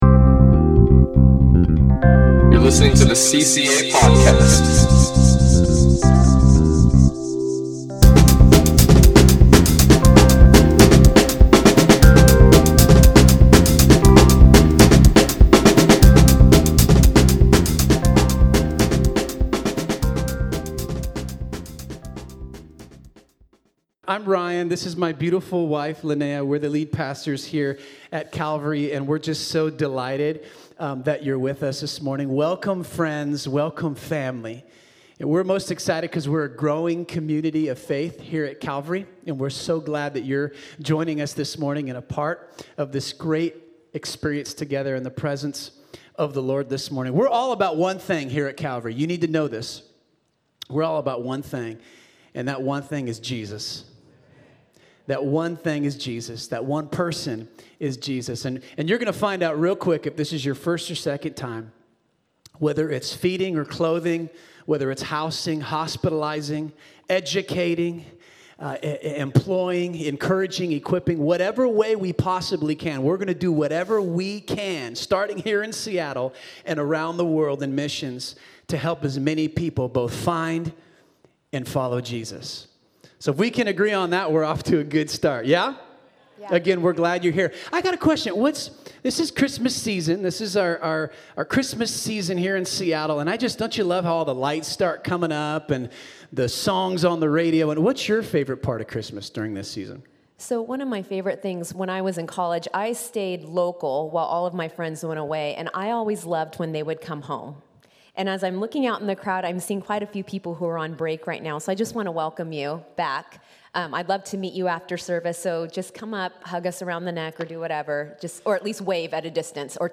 Message - Calvary Christian Assembly